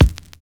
FINE BD    1.wav